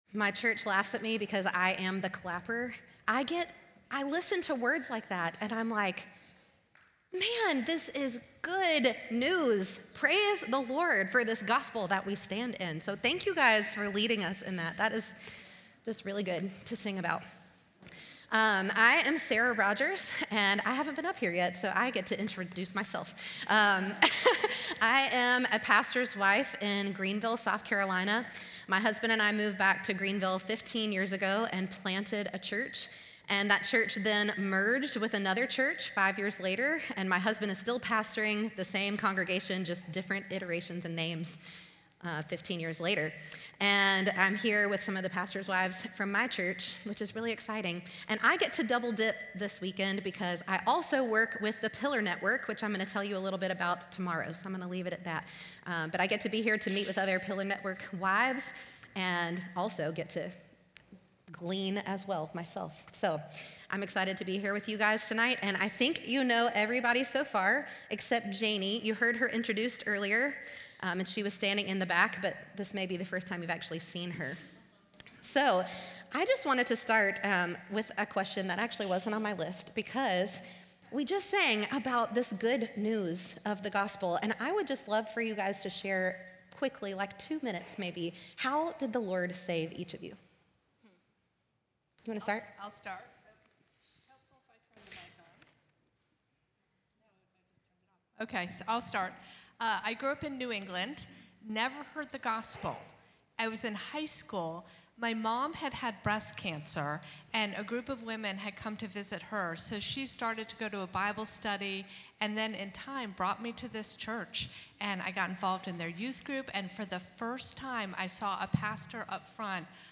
FMSPW 2024 Panel Discussion and Q&A
Audio recorded at Feed My Sheep for Pastors Wives Conference 2024.